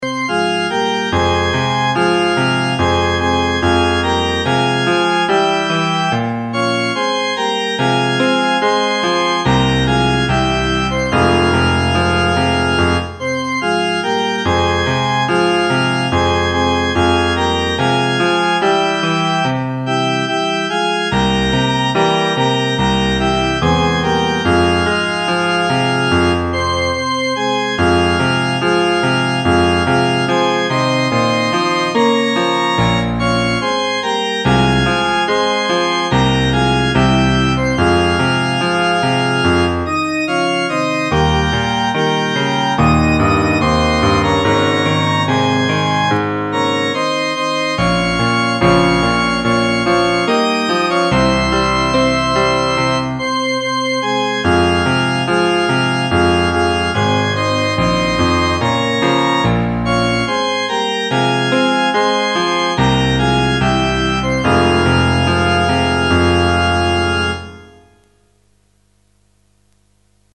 音色：チャーチオルガン＋ピアノ/設定：標準